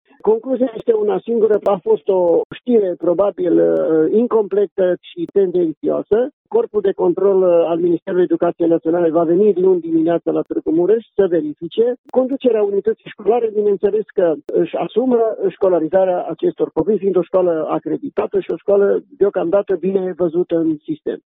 Șeful IȘJ Mureș spune că nu există însă dovezi clare care să incrimineze unitatea din Tîrgu Mureș: